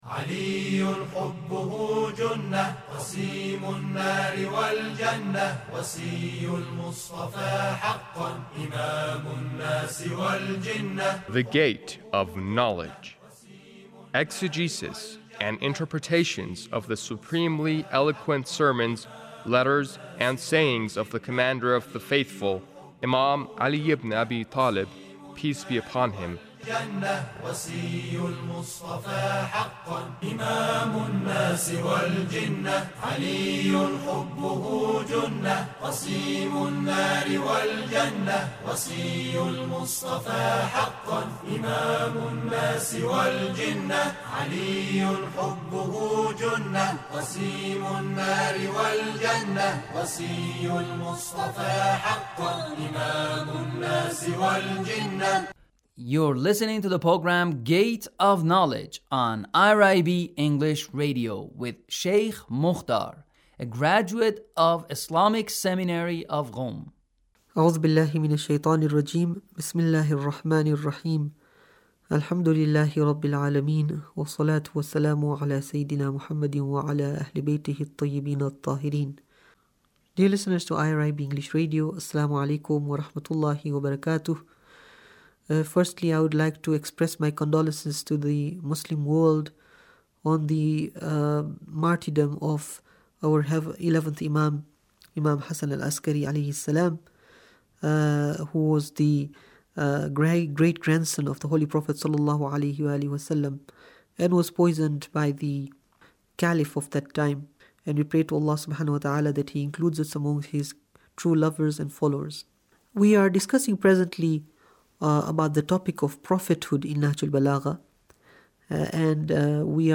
Sermon 1 - Prophethood 2